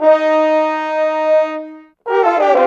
Rock-Pop 07 Horns 05.wav